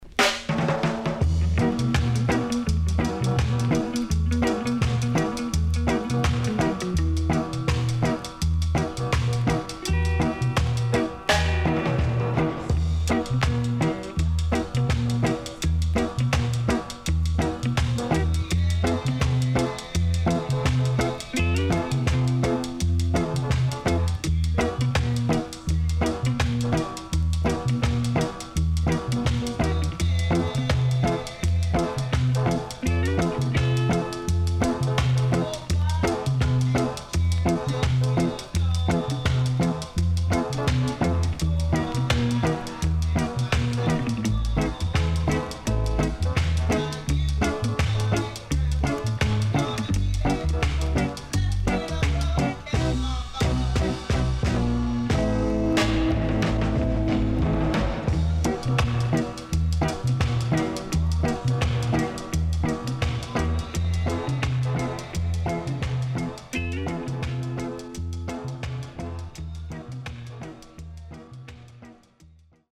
HOME > REGGAE / ROOTS
Killer Melodica Inst
SIDE A:少しチリノイズ入ります。